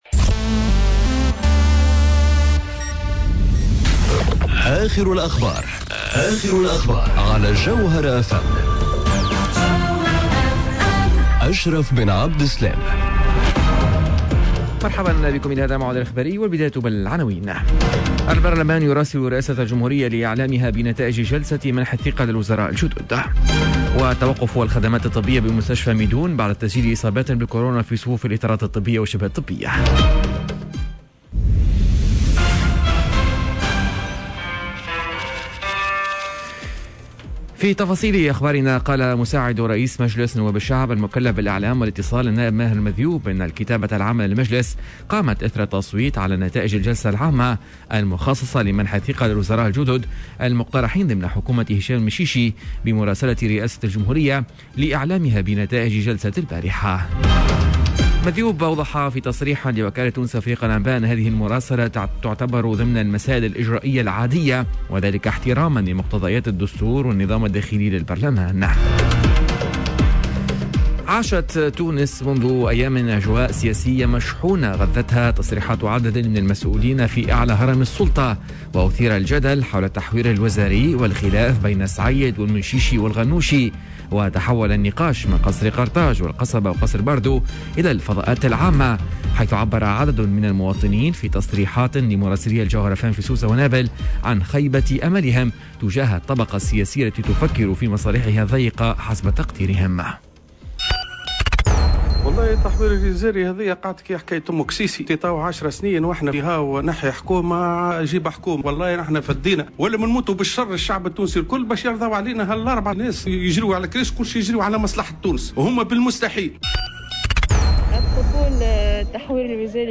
نشرة أخبار منتصف النهار ليوم الإربعاء 27 جانفي 2021